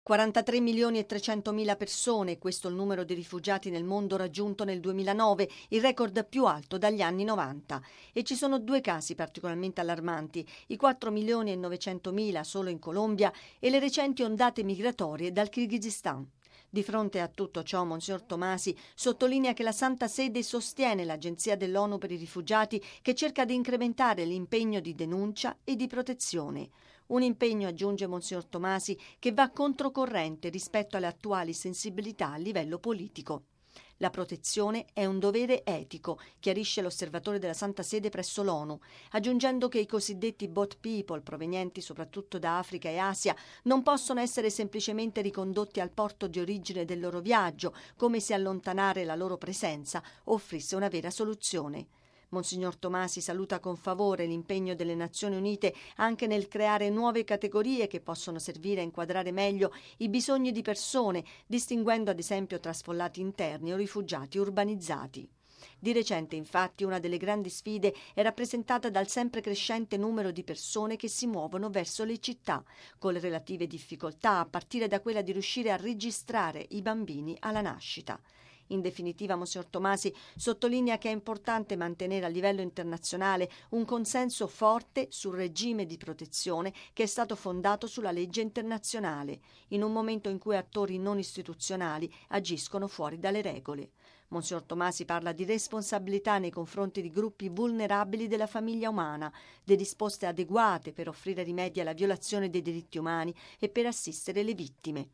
◊    Combinare sicurezza e rispetto della dignità e dei diritti dell’uomo: questa la raccomandazione dell’arcivescovo mons. Silvano Tomasi, osservatore permanente della Santa Sede presso le Nazioni Unite e altre Organizzazioni internazionali a Ginevra, intervenuto al 48.mo incontro del Comitato esecutivo dell’Agenzia dell’Onu per i Rifugiati (Acnur). Il servizio